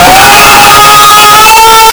ahhhhhhhhhhhhhhhhhh mega loud Meme Sound Effect
ahhhhhhhhhhhhhhhhhh mega loud.mp3